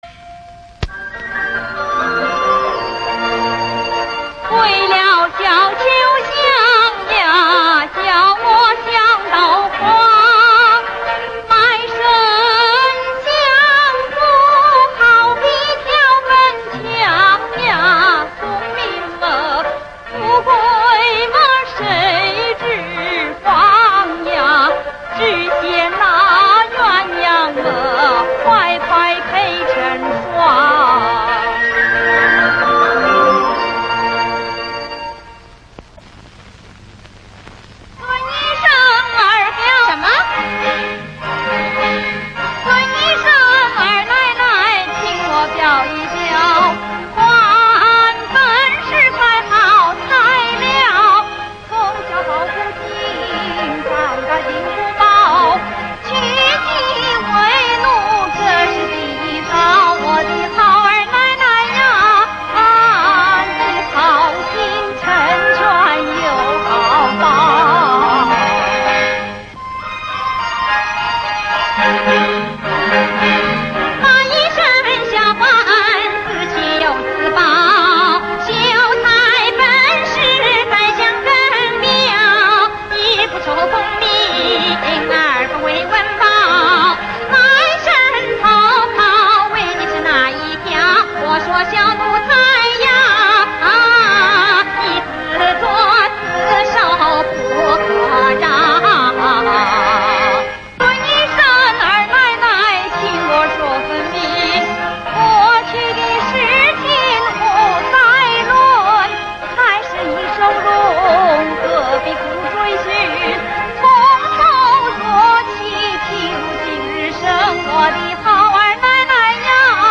把美丽的湖光景色、江南戏曲中的杂腔小调和充满喜剧色彩的唱词、念白交织一起，